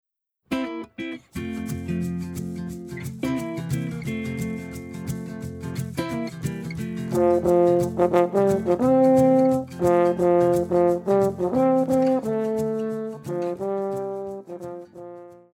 Pop
French Horn
Band
Instrumental
Rock
Only backing